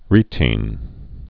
(rētēn, rĕtēn)